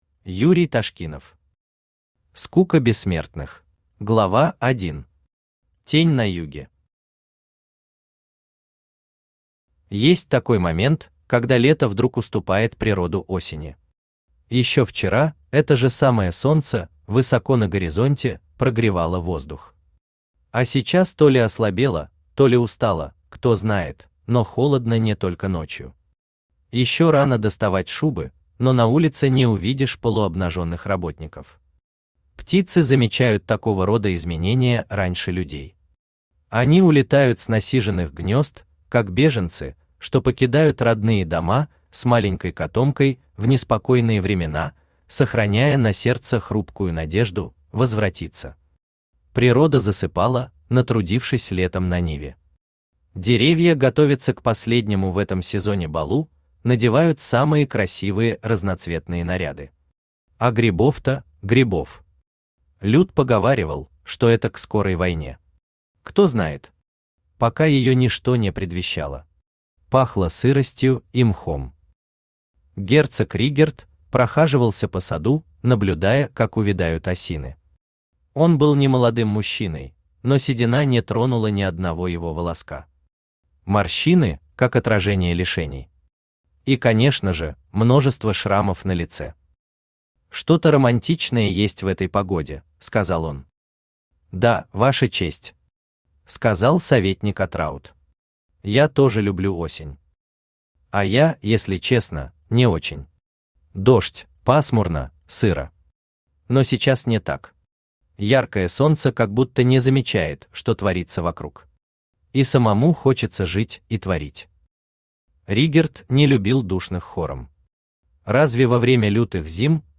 Aудиокнига Скука бессмертных Автор Юрий Андреевич Ташкинов Читает аудиокнигу Авточтец ЛитРес.